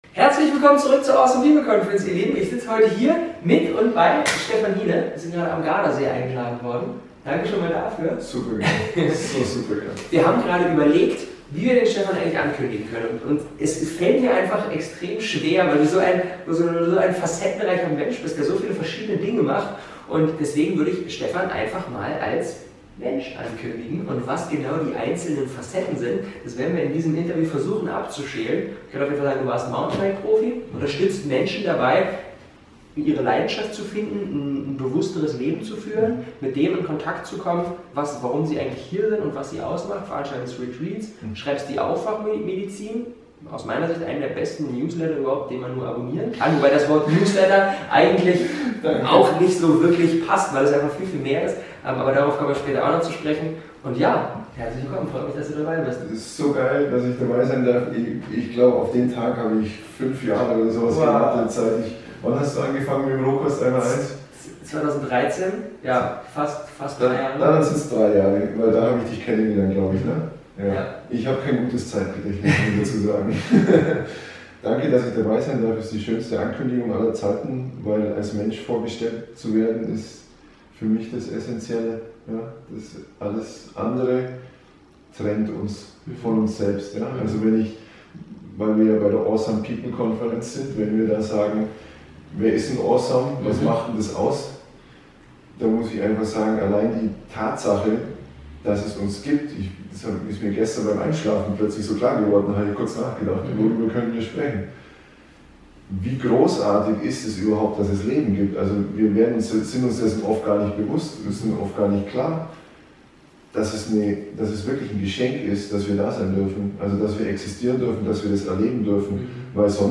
30 junge Menschen, die die Welt verändern - hol Dir die 26+ Stunden Interviewmaterial + fette Bonusgoodies